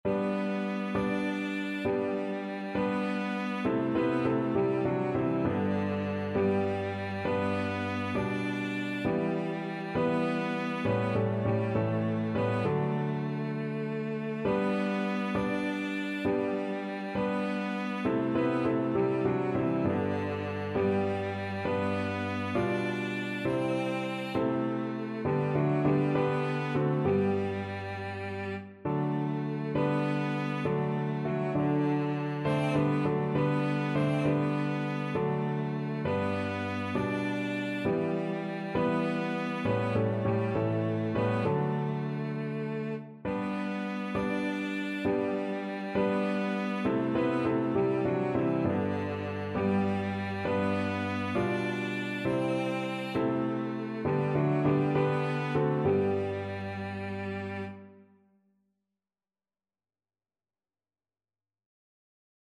Christian Christian Cello Sheet Music Praise Him!
Cello
G major (Sounding Pitch) (View more G major Music for Cello )
6/8 (View more 6/8 Music)
D4-D5
Classical (View more Classical Cello Music)